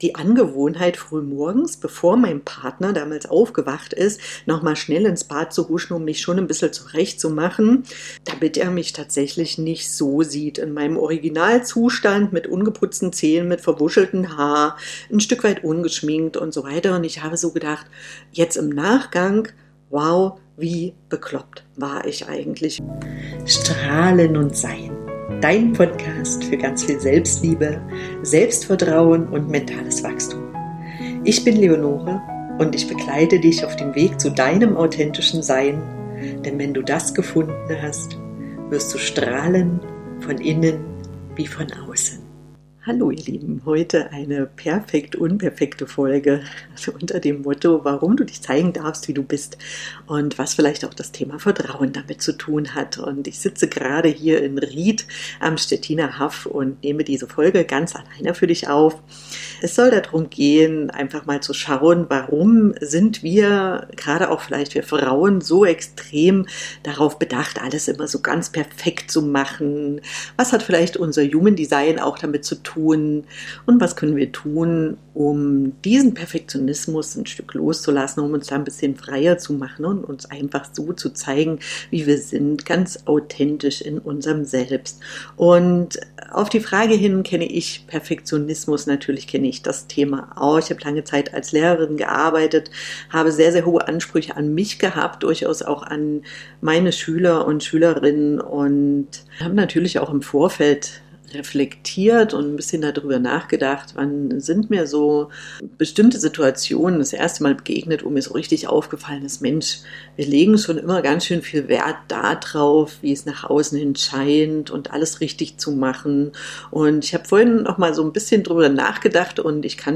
In dieser persönlichen Solo-Folge spreche ich über ein Thema, das viele von uns, bewusst oder unbewusst, begleitet: Perfektionismus.